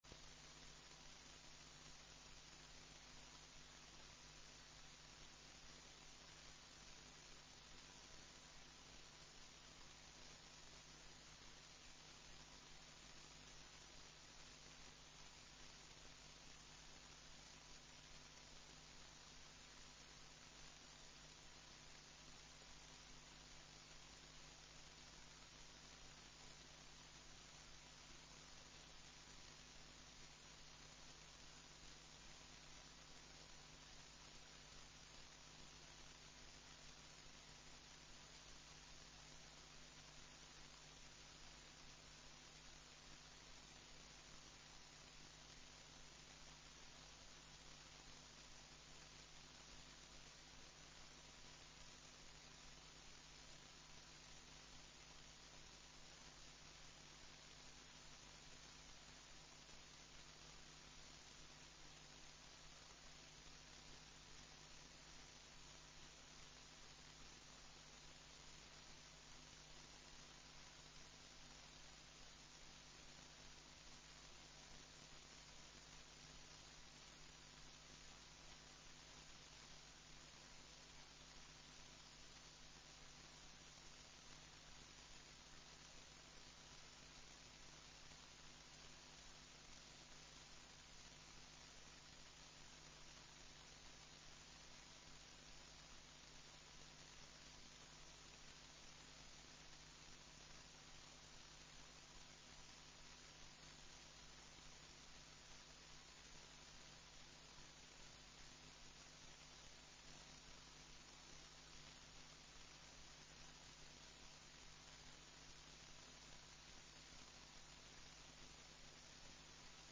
Město Litvínov: 4. zasedání Zastupitelstva města 16.02.2023 31a19b268e933d3f9f88e32b6a6e06ef audio